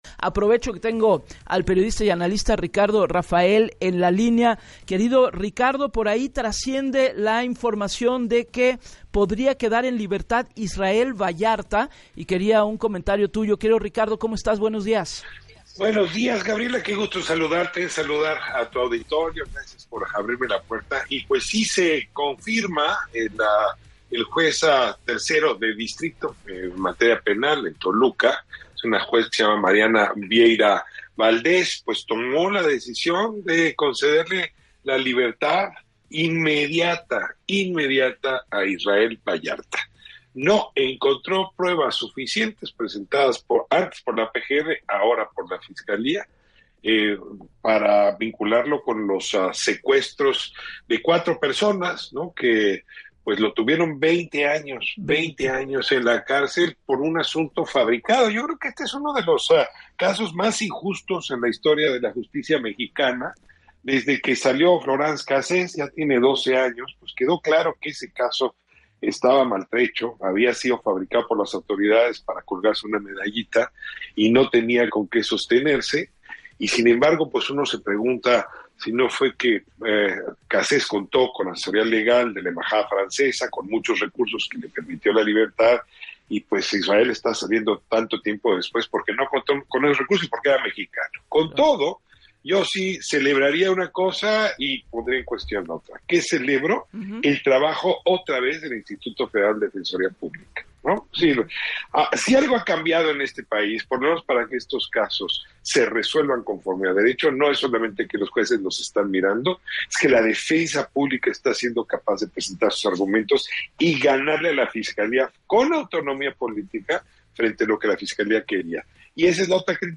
En entrevista para “Así las Cosas” con Gabriela Warkentin, Ricardo Raphael calificó el proceso como “uno de los casos más injustos en la historia de la justicia mexicana”.